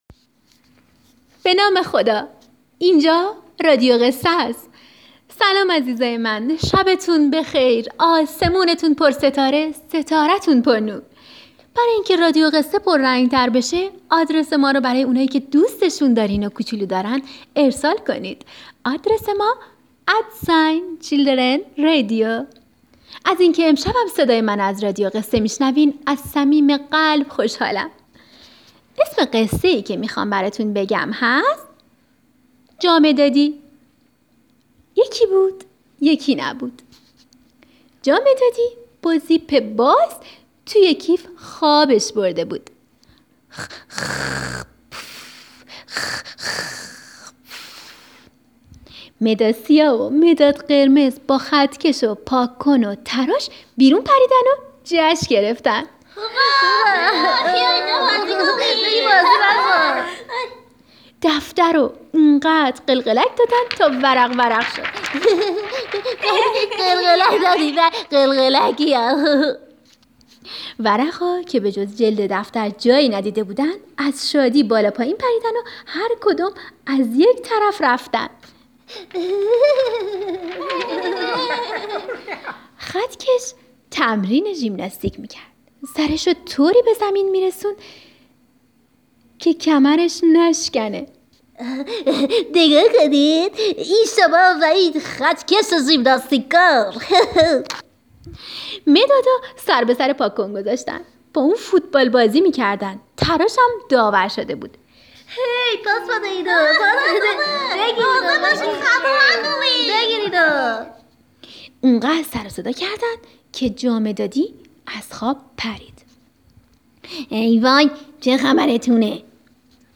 جامدادی-قصه کودکانه صوتی - رادیو قصه صوتی کودکانه